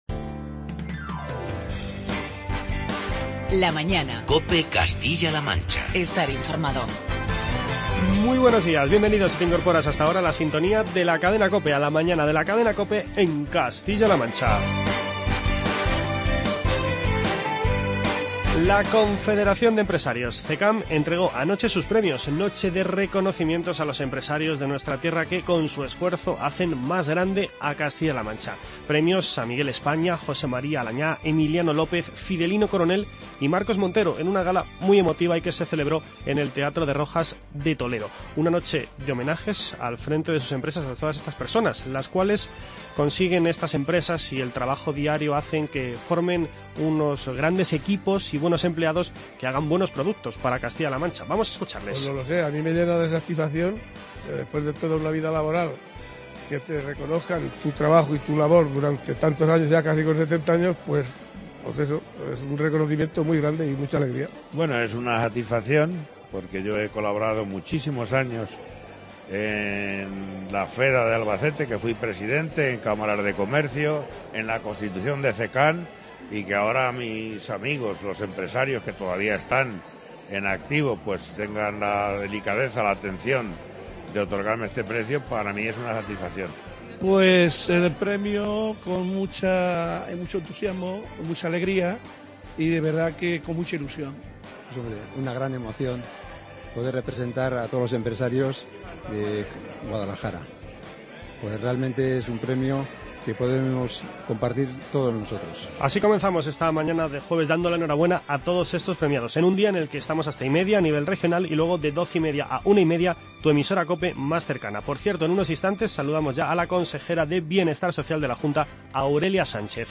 Charlamos con la consejera de Bienestar Social, Aurelia Sánchez